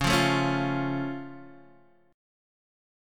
Db+ chord